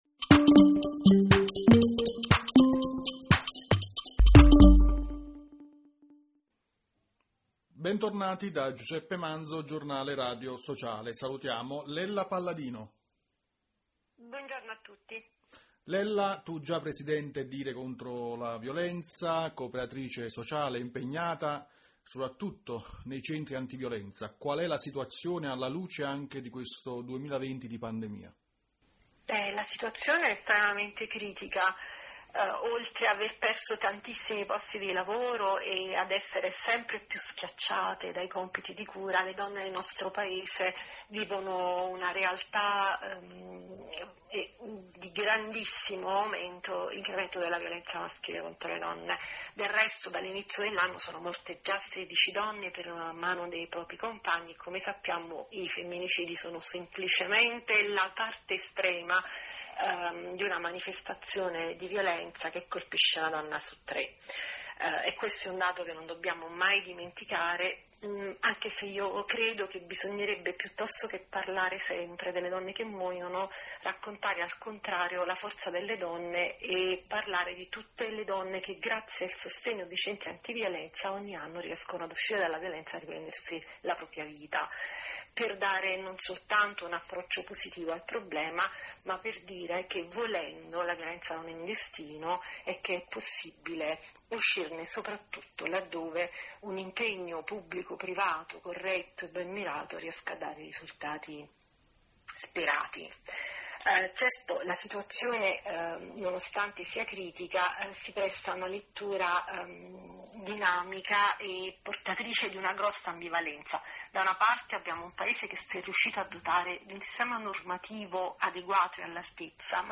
8 marzo e la violenza sulle donne: la situazione con la crisi dovuta alla pandemia e la situazione nei centri antiviolenza, quali politiche per il contrasto e l’inclusione. Intervista